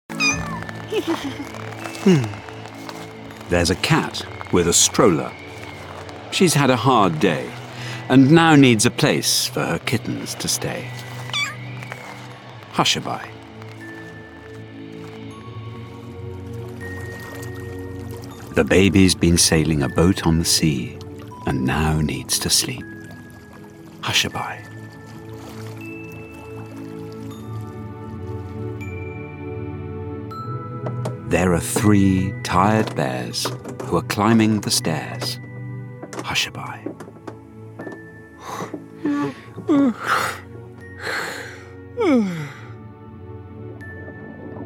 Avocado Baby and Other Stories Audiobook
Read by Alexander Armstrong.